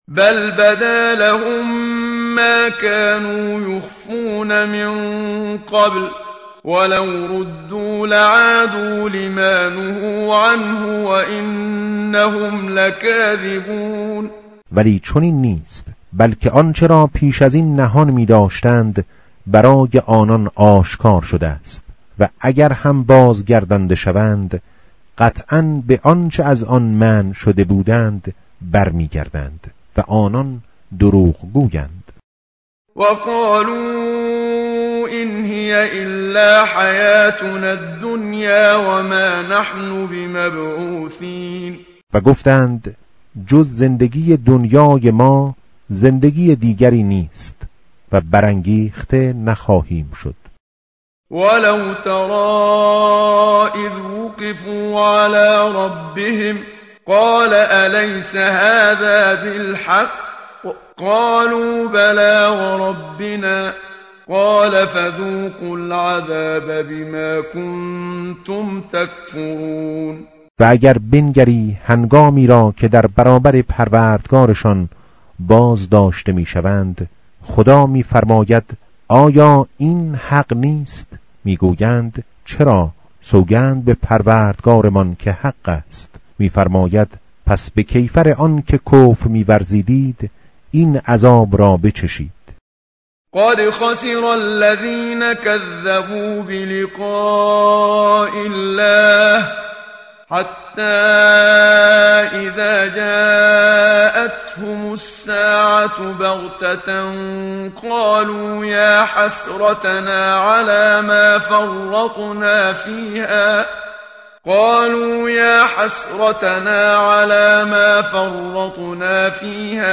tartil_menshavi va tarjome_Page_131.mp3